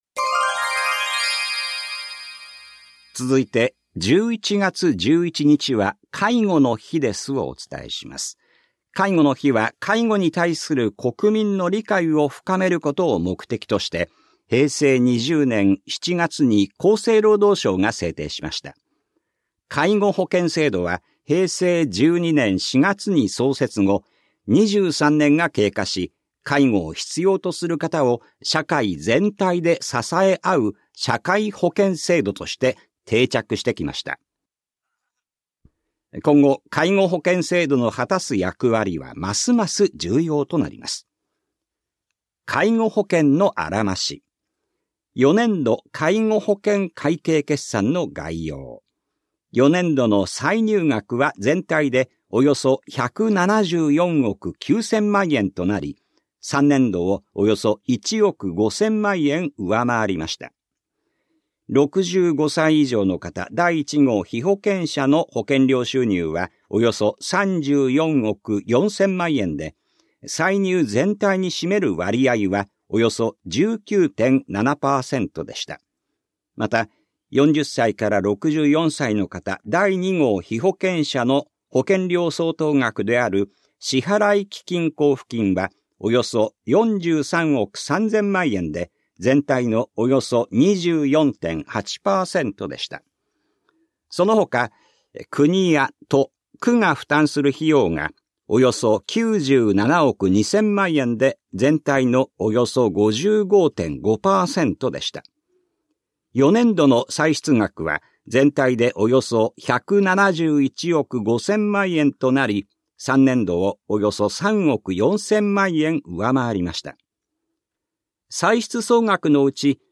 広報「たいとう」令和5年10月20日号の音声読み上げデータです。